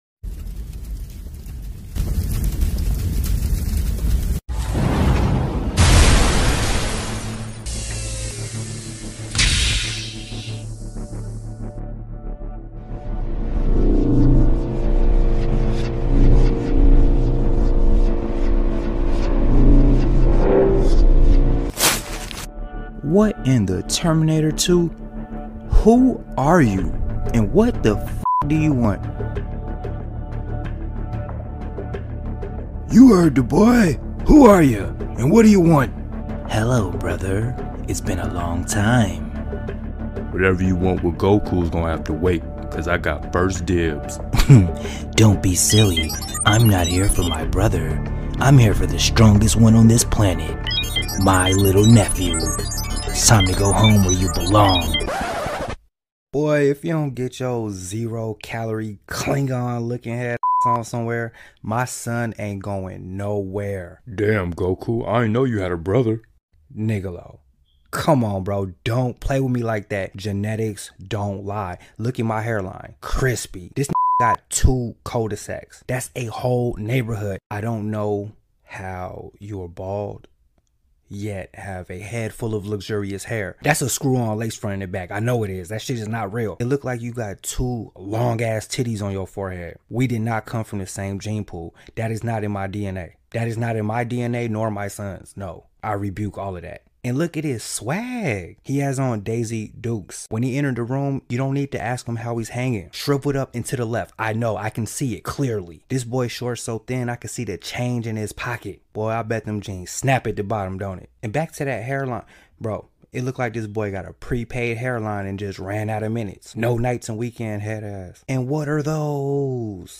Goku Meets Raditz But They're Sound Effects Free Download